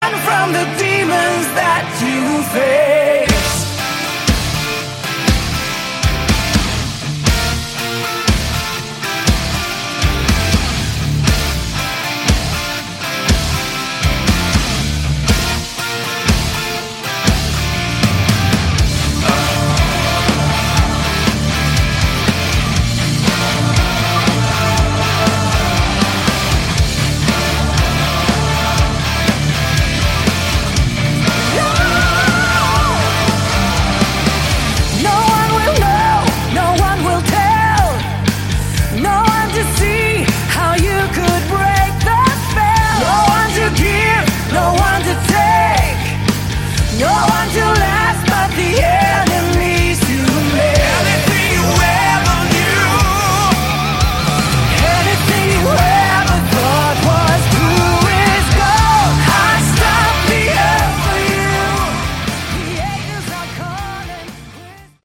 Category: Melodic Rock
lead vocals
keyboards
lead guitars, backing vocals
drums
bass